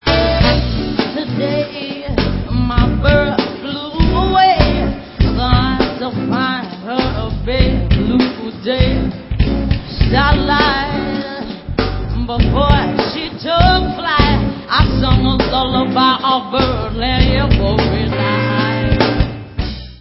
T In The Park 2004
Pop